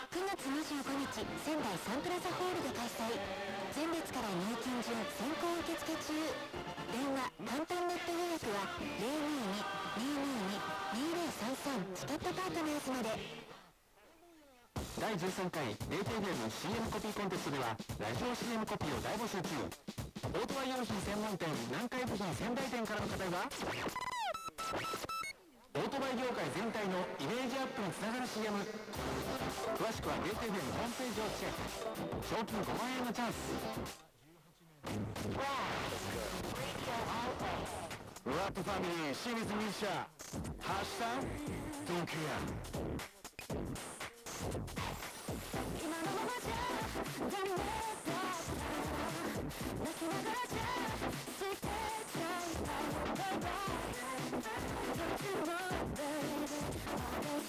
歪音が苦手な方は，録音データを再生しないようお願いいたします・・・。
27.9km　大高森_山頂_ＺＬスペシャル3素子アンテナ（93.5MHz用）
アンプを介さないイヤホンで　放送内容が十分わかる品質と音量が得られました